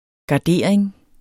Udtale [ gɑˈdeˀɐ̯eŋ ]